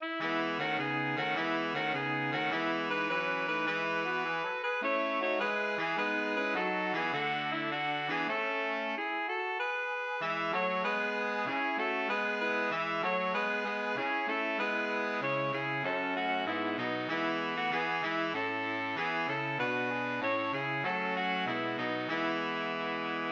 The verses are sung in 6/8 time, while the chorus switches to common time.
Melody